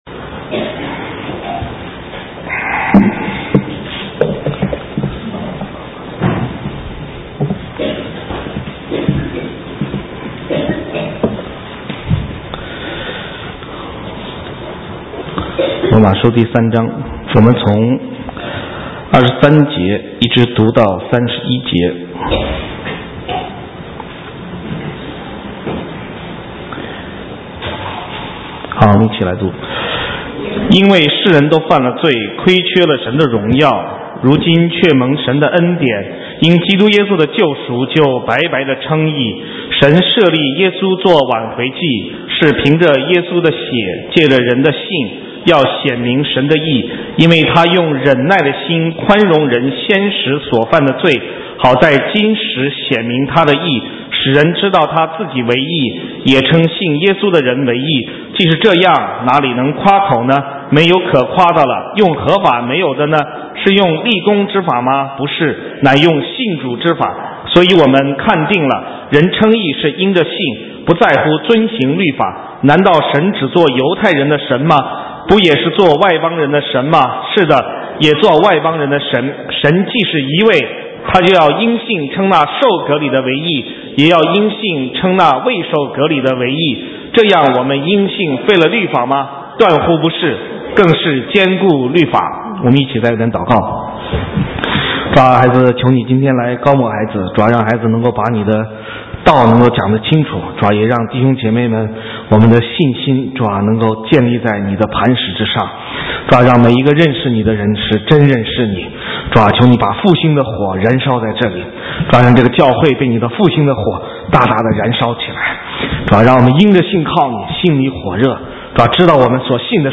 神州宣教--讲道录音 浏览：唯独耶稣 (2012-02-26)